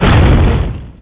mega_bouncehard4.wav